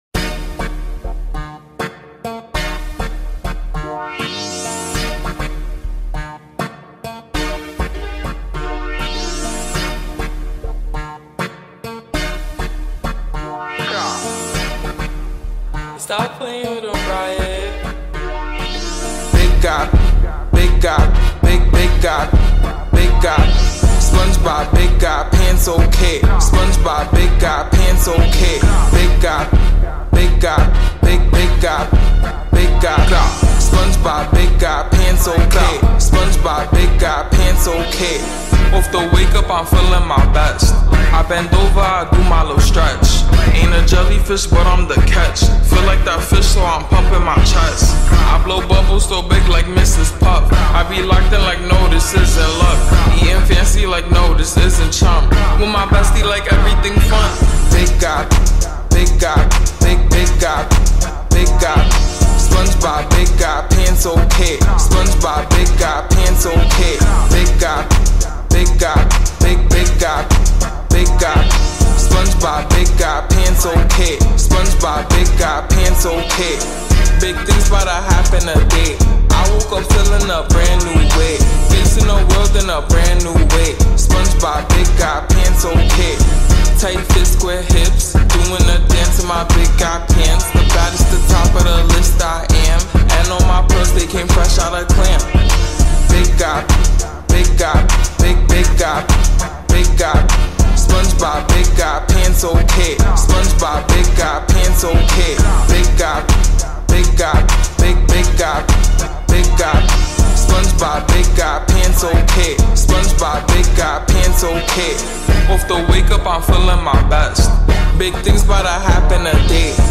Slowed and reverb
با ریتمی کند شده